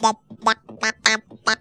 BEATVOICE2.wav